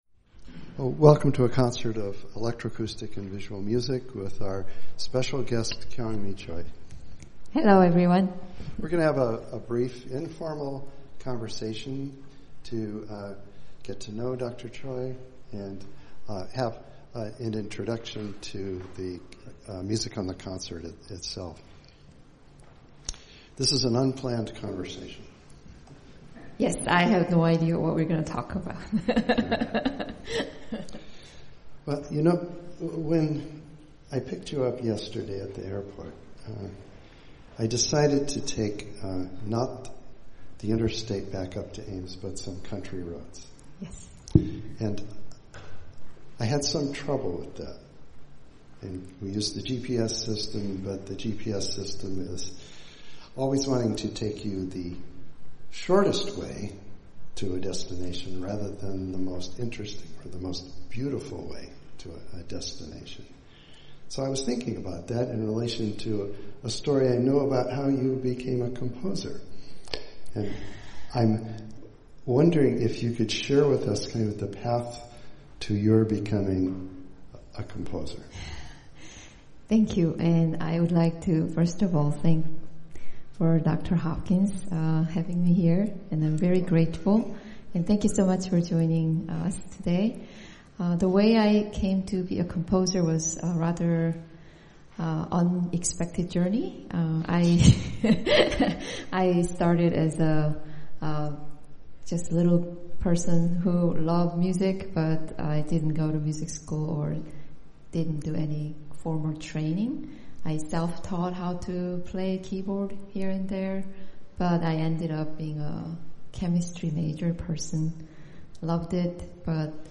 Iowa State Uni Pre-Concert talk.mp3